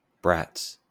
Ääntäminen
Ääntäminen US Tuntematon aksentti: IPA : /bɹæts/ Haettu sana löytyi näillä lähdekielillä: englanti Kieli Käännökset bulgaria дечурлига (dečurlíga) Brats on sanan brat monikko.